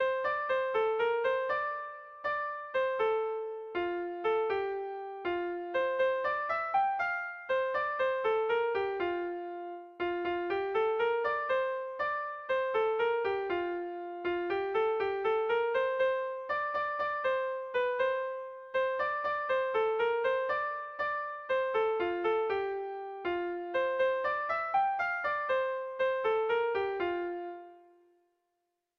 Sentimenduzkoa
ABDEA2B